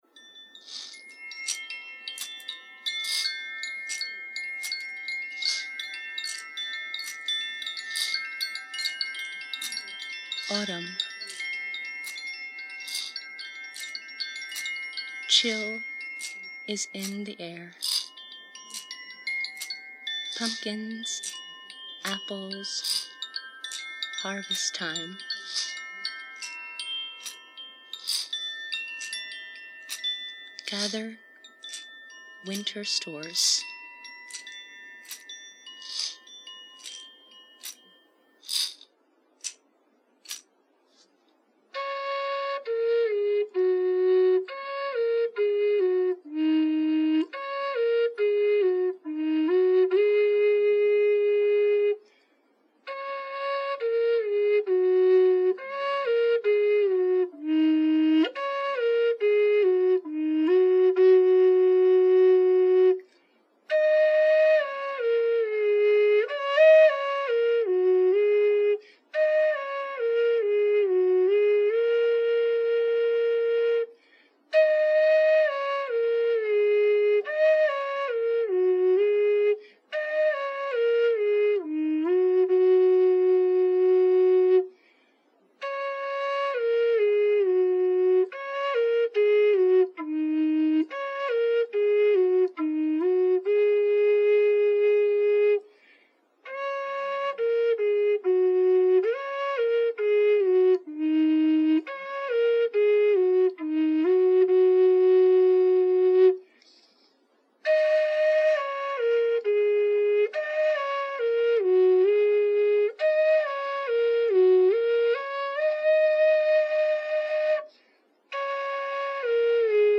autumn-flutehaiku.mp3